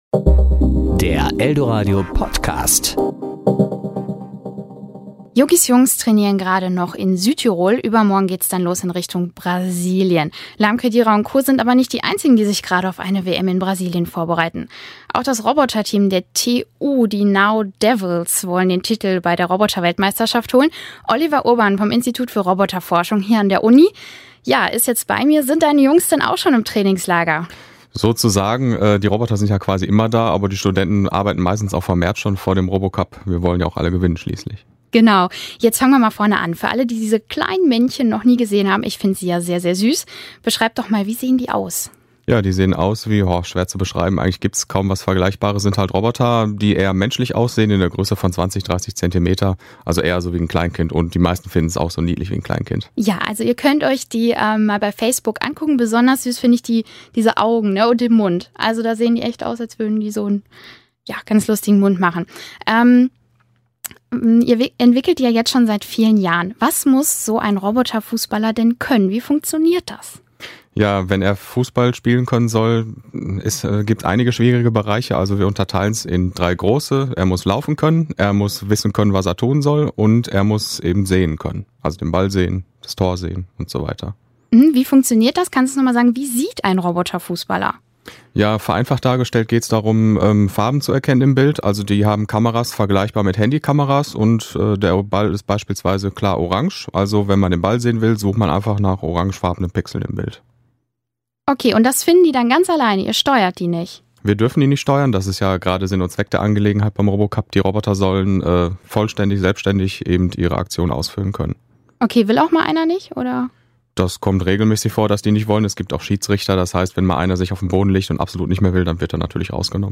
Interview: Fußballroboter der TU
Denn auch kleine Roboter der TU reisen nach Brasilien, um dort kickend Weltmeister zu werden. Wie das funktioniert, hat uns einer der Macher im Interview verraten.